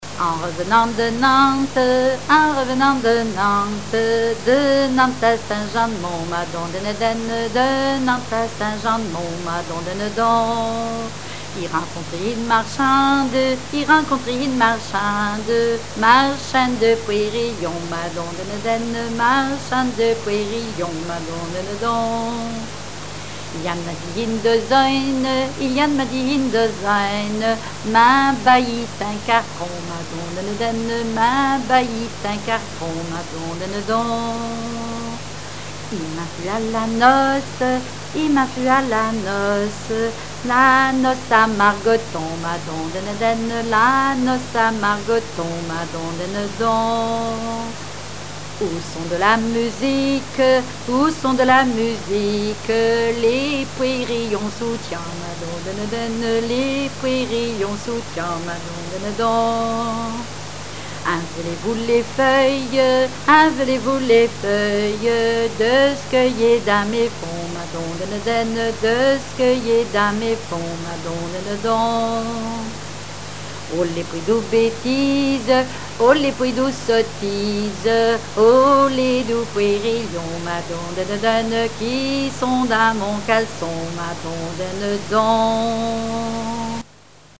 Mémoires et Patrimoines vivants - RaddO est une base de données d'archives iconographiques et sonores.
Patois local
Genre laisse
répertoire de chansons populaire et traditionnelles
Pièce musicale inédite